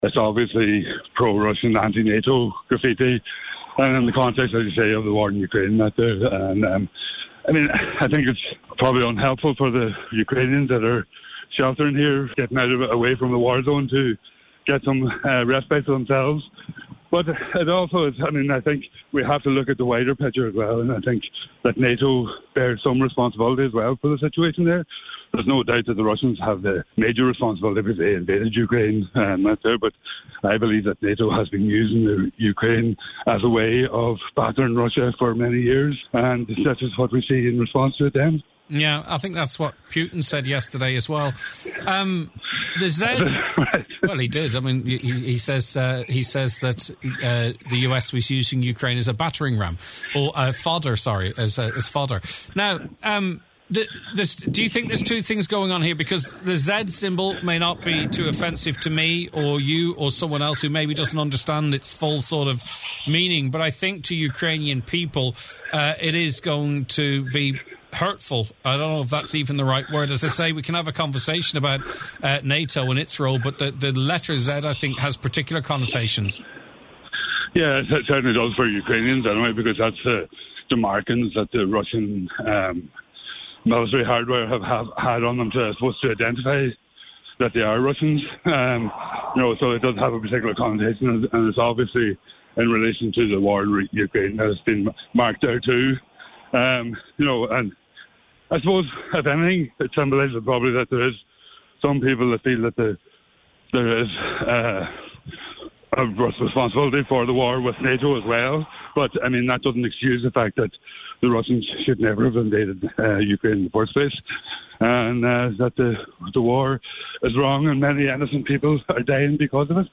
Donegal Deputy Thomas Pringle says while this is not helpful, he believes NATO is not an honest broker in this conflict…………